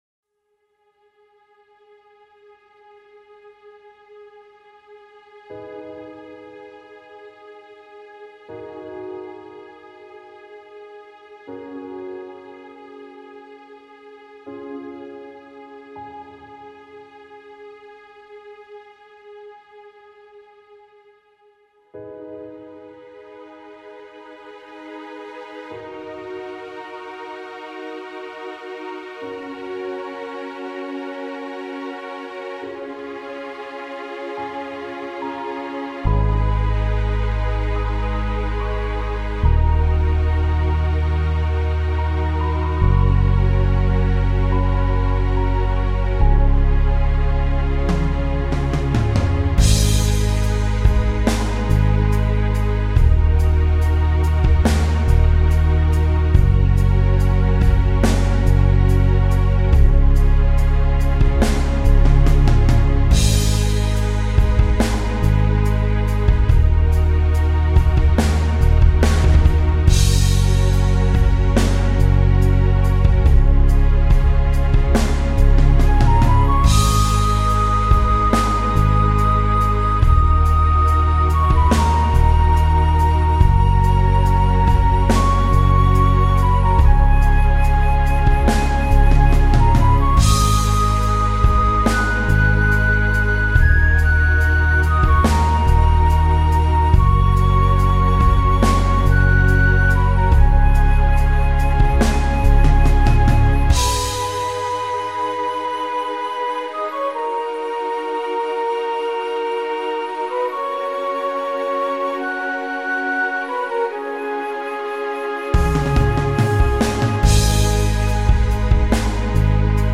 genre:orchestral rock